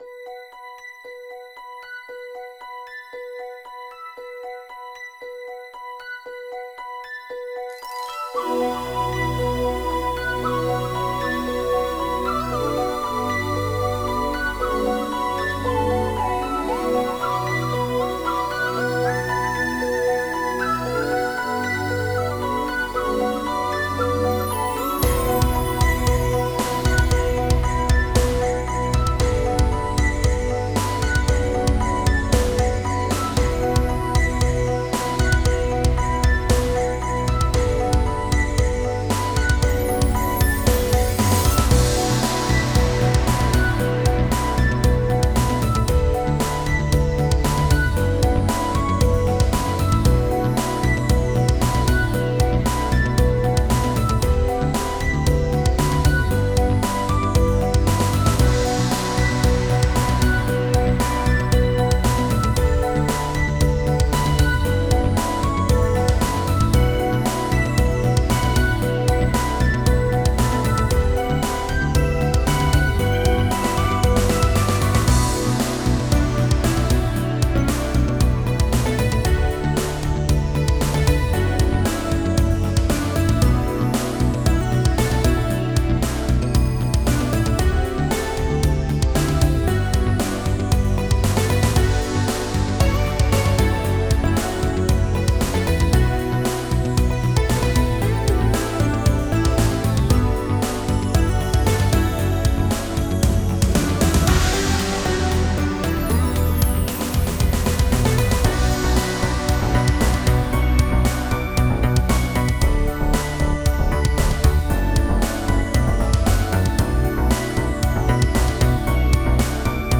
4:44 Style: Synthpop Released